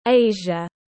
Asia /ˈeɪ.ʒə/